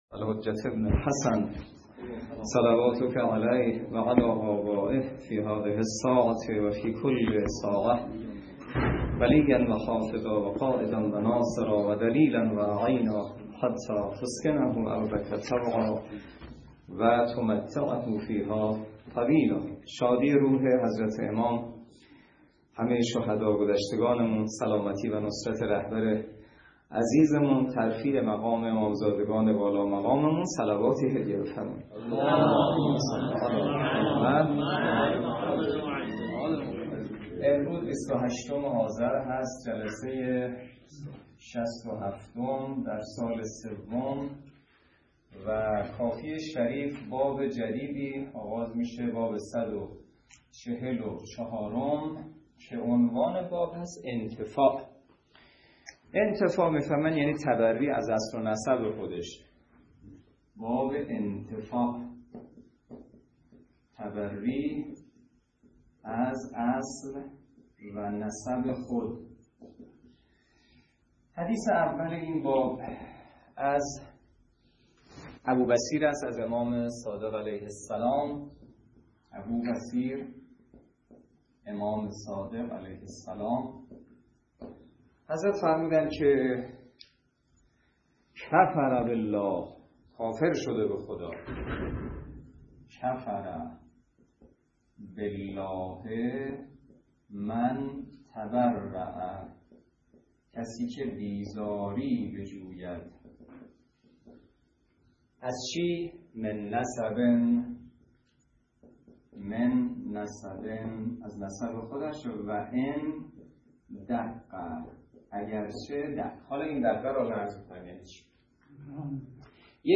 درس فقه حجت الاسلام والمسلمین سید سعید حسینی نماینده مقام معظم رهبری در منطقه و امام جمعه کاشان موضوع: فقه اجاره - جلسه ۶۷